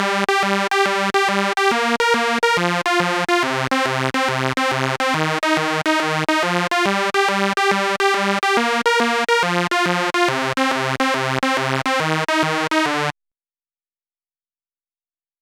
VTDS2 Song Kit 14 Pitched Freaking Synth Octaver.wav